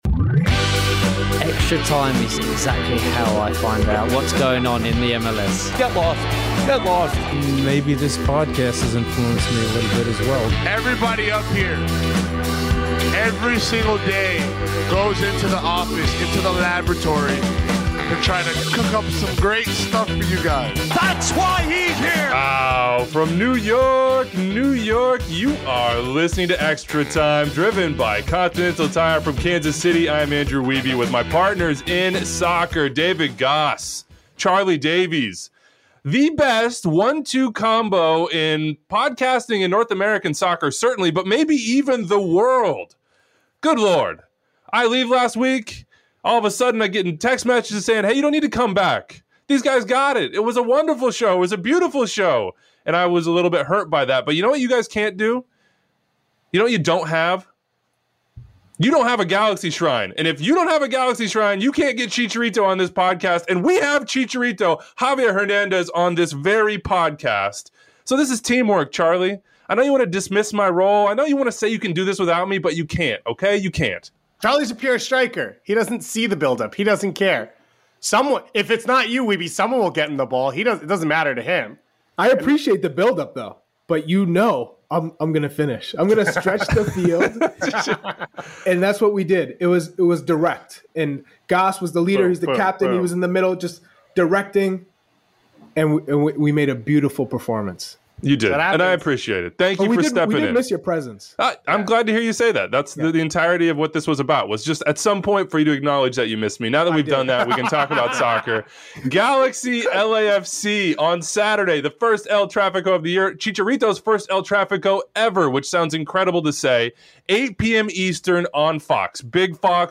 Ahead of El Trafico, the guys chat with the El Tri legend and ponder Carlos Vela’s status as well as the stakes for this edition of the rivalry.